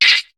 Cri de Nénupiot dans Pokémon HOME.